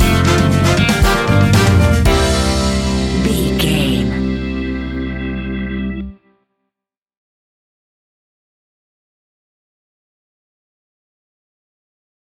Ionian/Major
flamenco
romantic
maracas
percussion spanish guitar